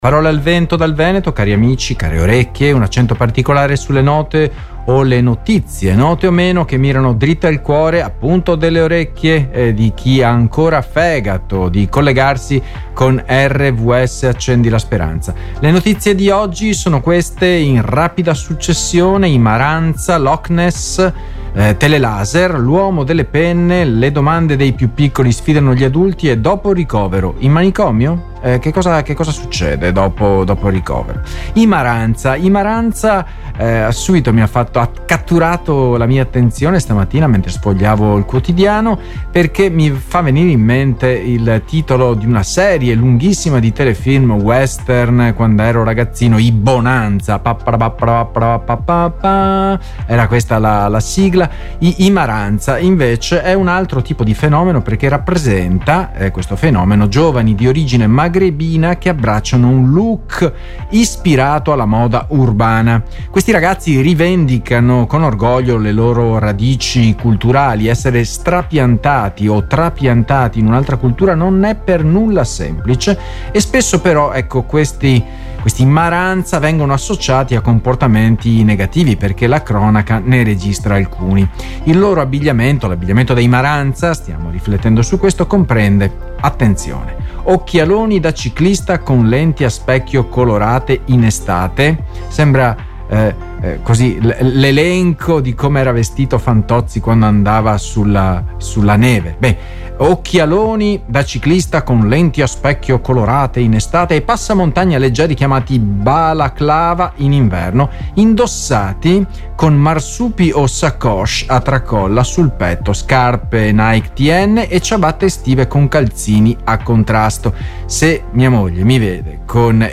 Parola al vento dal Veneto, un accento particolare sulle note e le notizie note o meno che mirano dritte al cuore delle orecchie che hanno fegato.